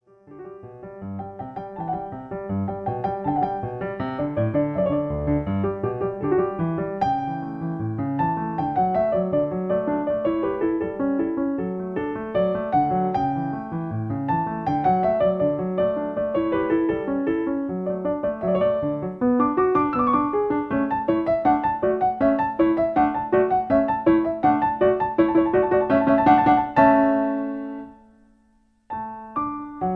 Original key. Slower tempo. Piano Accompaniment